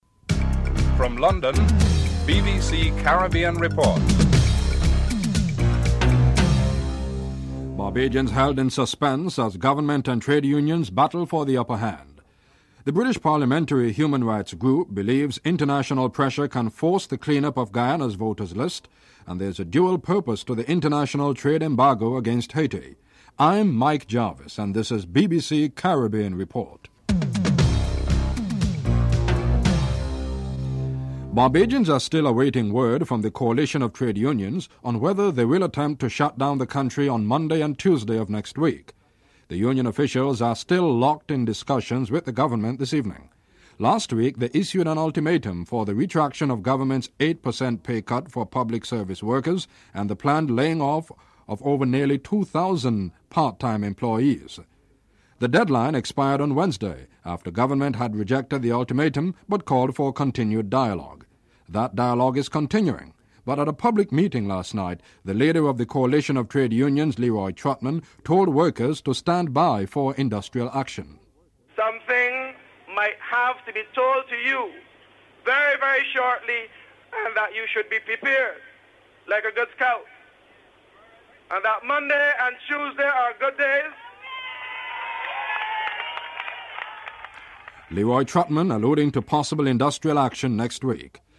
1. Headlines (00:00-00:30)
4. The decision to impose an international trade embargo against Haiti was implemented to serve a dual purpose: to stifle the military takeover and to send a warning to others contemplating future military coups. Comments from Bernard Aronson, US Assistant Secretary of State for Latin America (10:07-13:06)